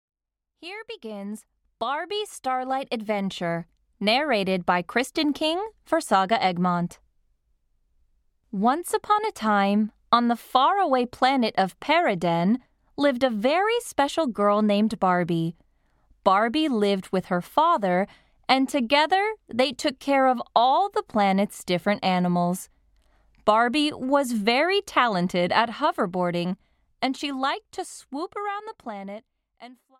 Audio knihaBarbie - Starlight Adventure (EN)
Ukázka z knihy